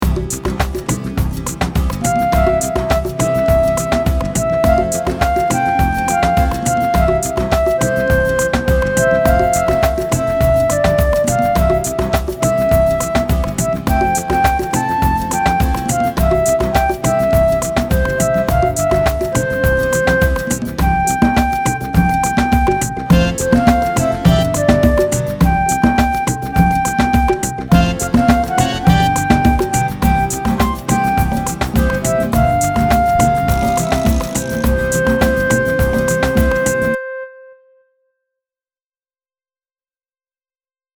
Oh God Today backing
Oh_God_Today_backing.mp3